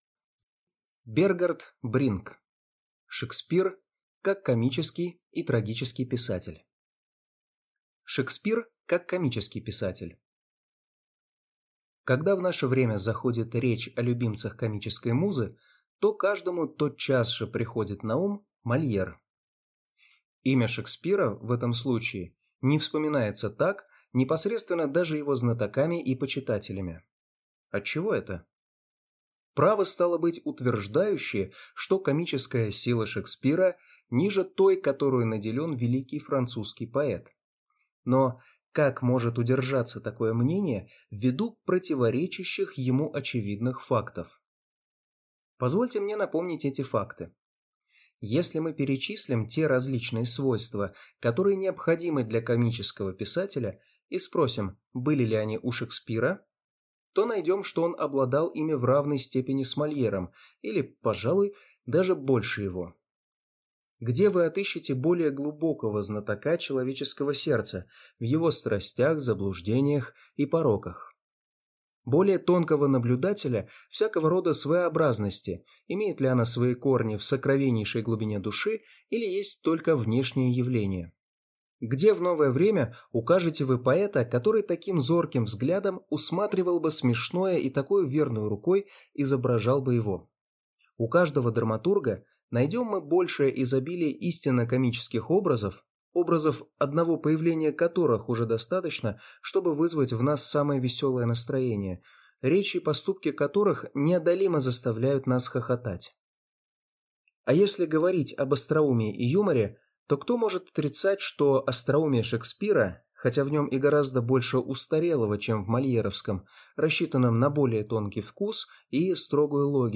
Аудиокнига Шекспир, как комический и трагический писатель | Библиотека аудиокниг